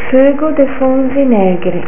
(pronuncia)   funghi porcini, salsa di pomodoro, olio